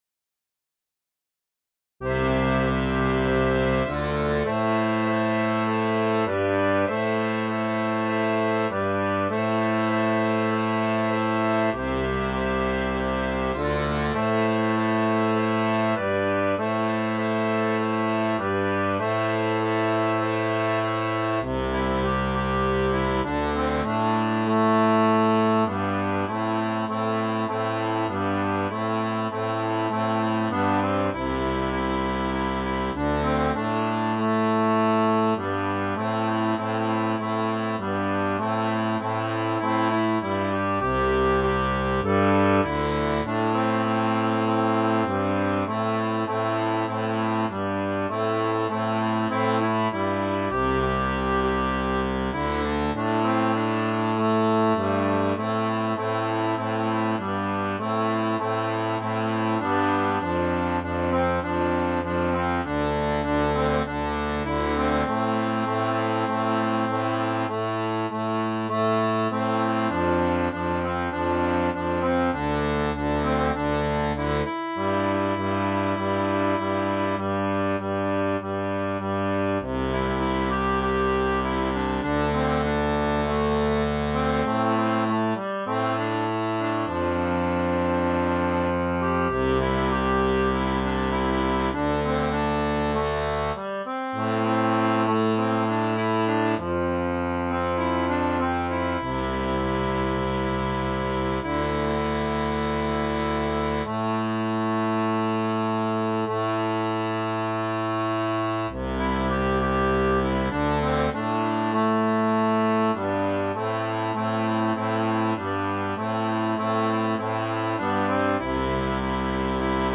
• Une tablature pour diato à 2 rangs
Cette version est simplifiée.
Pop-Rock